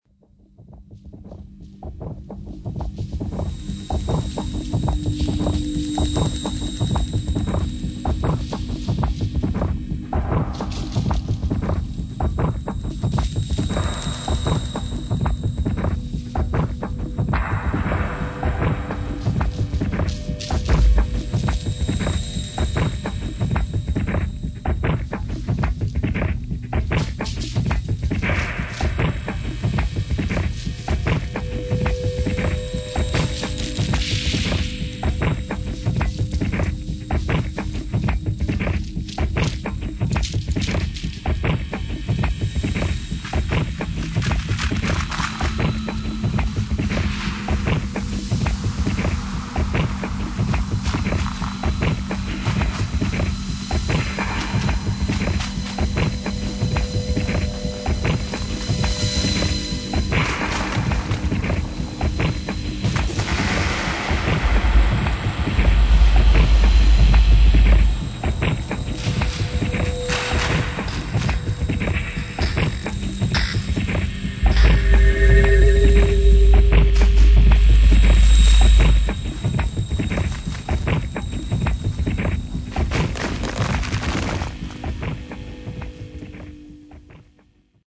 [ EXPERIMENTAL / TECHNO / DRONE / BASS ]